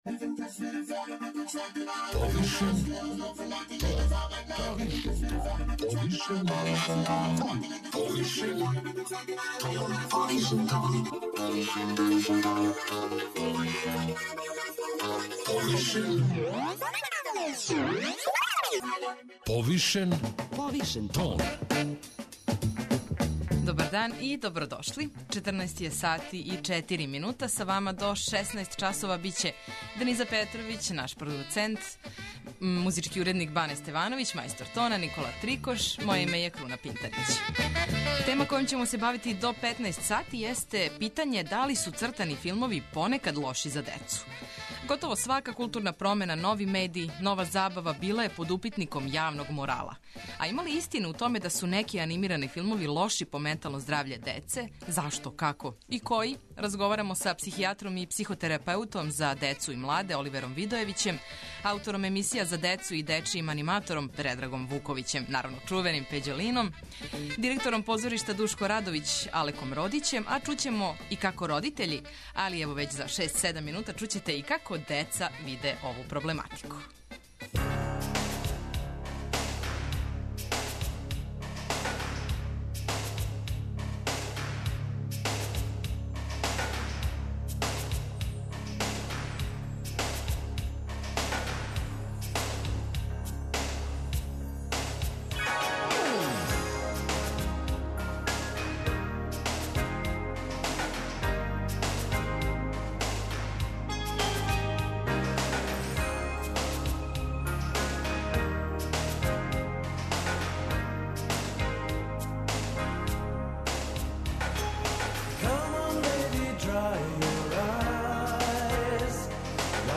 а чућемо и како родитељи, али и деца виде ову проблематику.